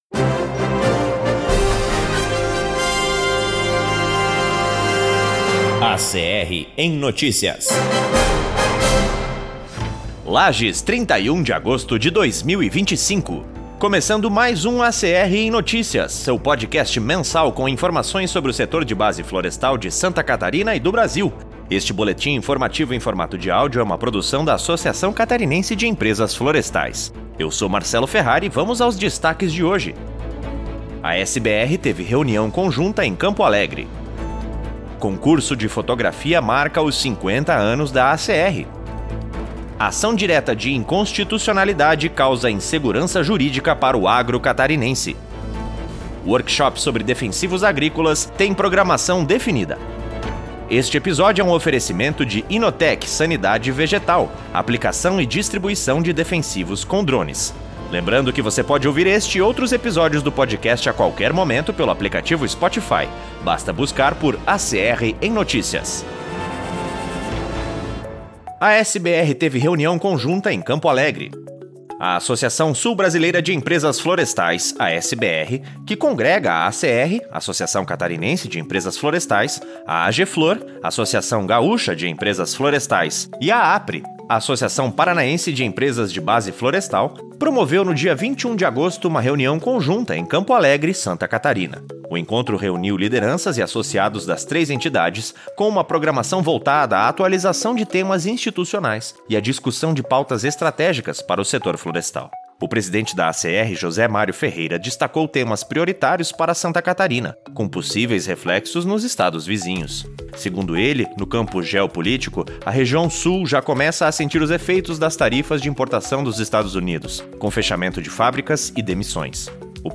Este boletim informativo em formato de áudio é uma produção da Associação Catarinense de Empresas Florestais.